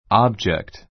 object ɑ́bdʒikt ア ブ ヂェ クト 名詞 ❶ 物, 物体 I saw a strange object in the sky.